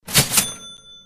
Caja Registradora Efecto Sonido